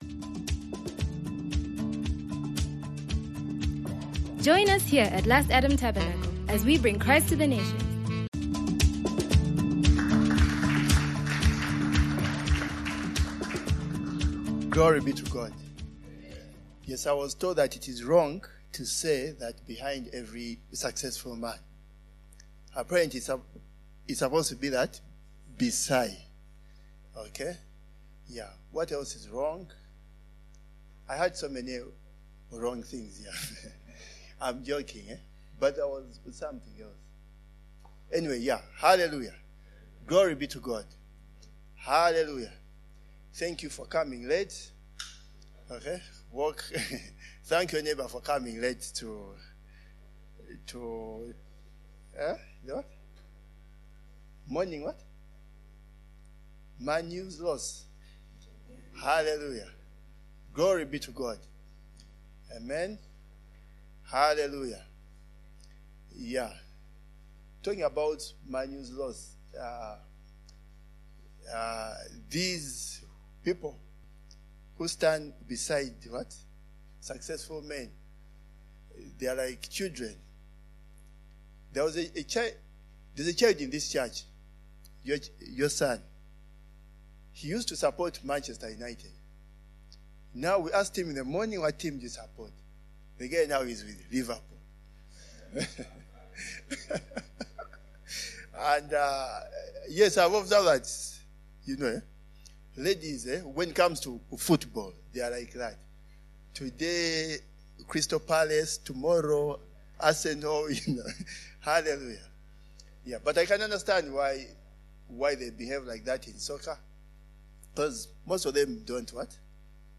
For every one of us, God is able and willing to do exceedingly abundantly above all that we ask. This sermon teaches how we can make this happen in our lives.